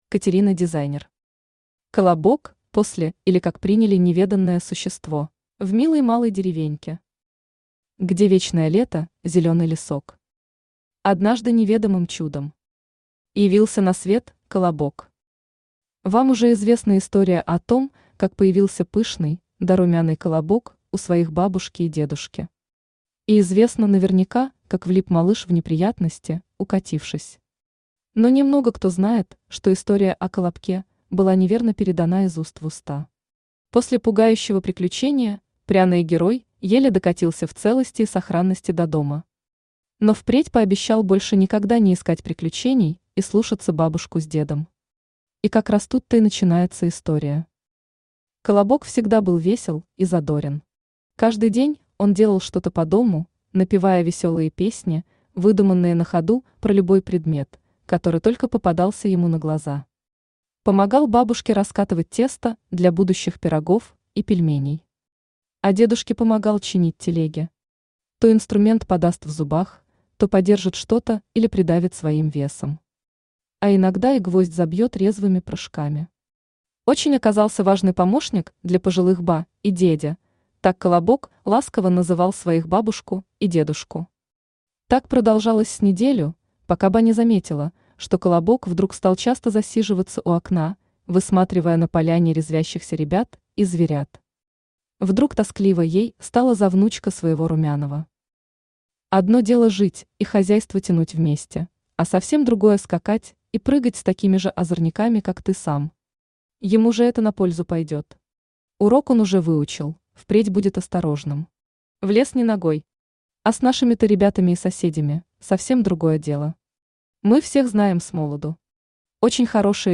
Аудиокнига Колобок, после или Как приняли неведанное существо!
Автор Катерина Дизайнер Читает аудиокнигу Авточтец ЛитРес.